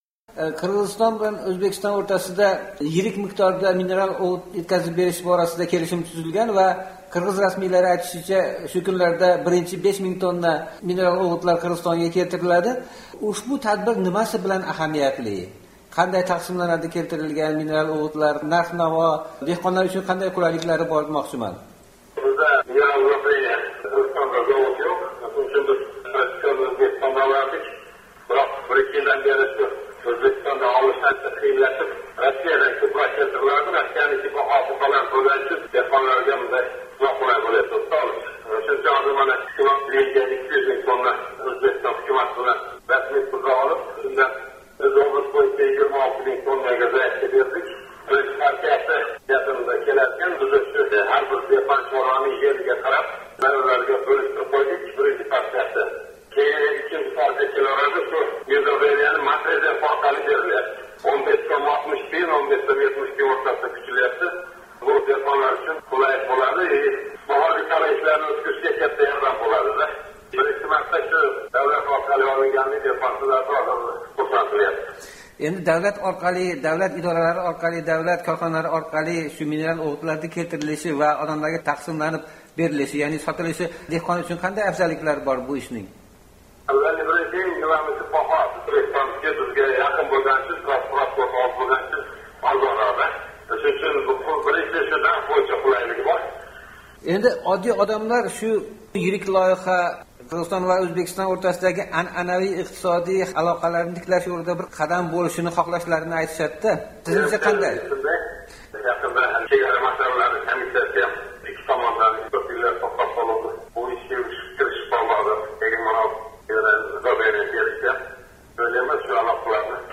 Qirg’iziston hukumatining O’sh viloyatidagi muxtor vakili o’rinbosari Ahmadjon Mahamadov bilan suhbatda bo’ldik.